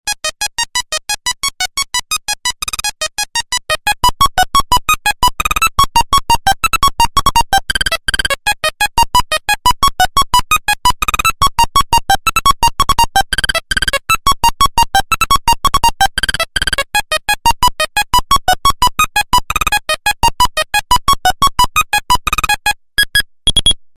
Звук мелодии пищалки